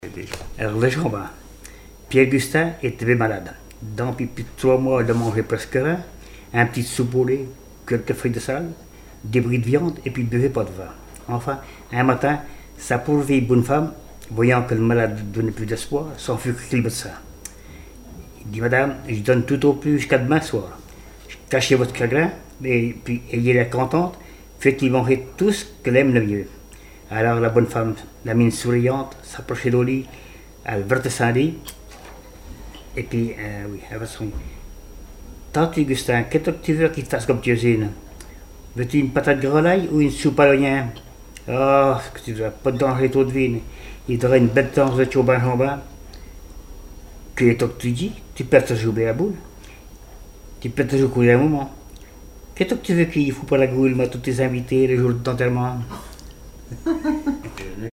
Genre sketch
Enquête Arexcpo en Vendée-Association Joyeux Vendéens
Catégorie Récit